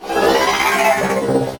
CosmicRageSounds / ogg / general / combat / enemy / droid / die3.ogg